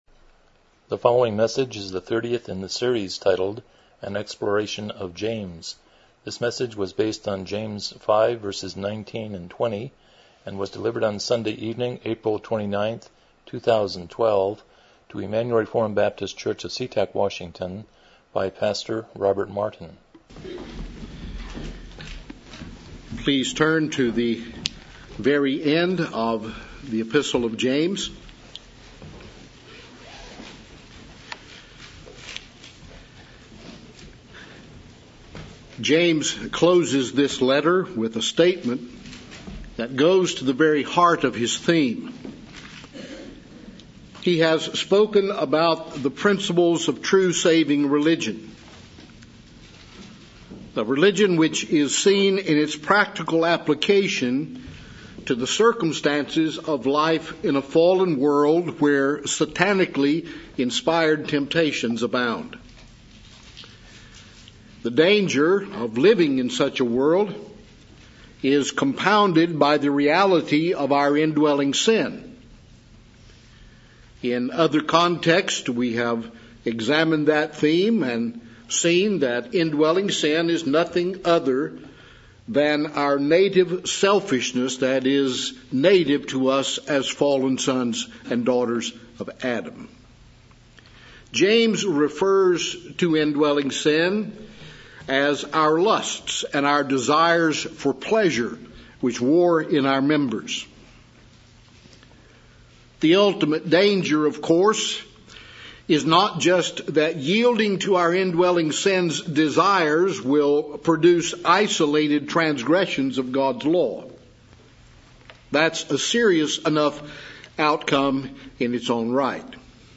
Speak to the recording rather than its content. James 5:19-20 Service Type: Evening Worship « 168 Romans 16:20b